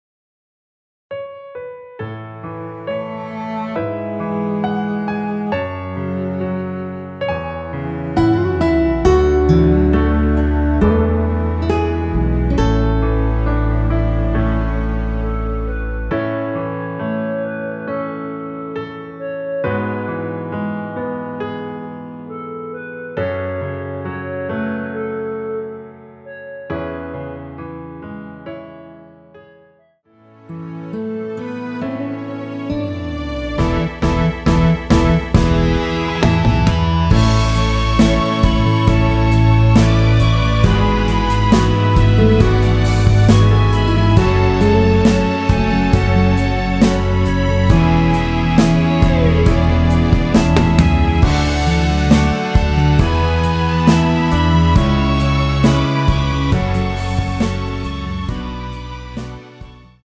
원키 멜로디 포함된 MR입니다.
멜로디 MR이라고 합니다.
앞부분30초, 뒷부분30초씩 편집해서 올려 드리고 있습니다.
중간에 음이 끈어지고 다시 나오는 이유는